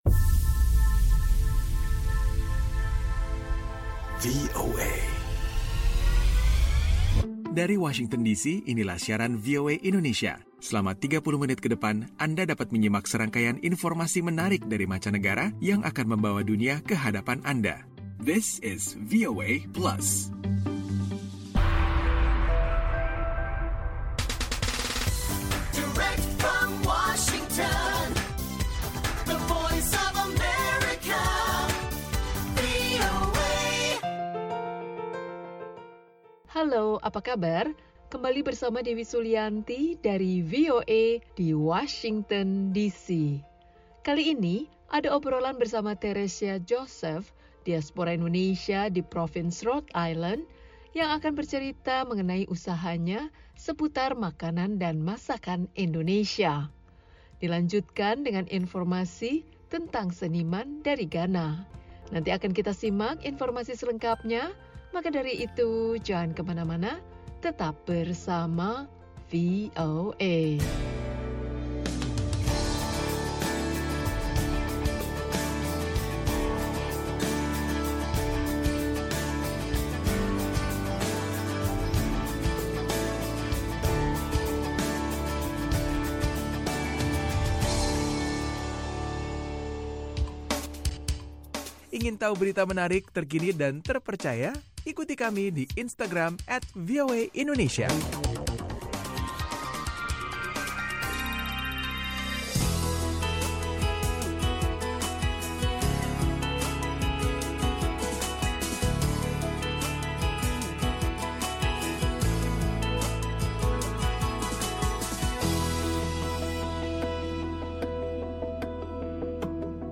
VOA Plus kali ini akan mengajak anda mengikuti obrolan bersama seorang diaspora Indonesia di negara bagian Rhode Island tentang kesibukannya sebagai agen kurir internasional serta usaha online makanan dan masakan Indonesia. Ada pula info tentang pameran yang digelar oleh seorang seniman asal Ghana.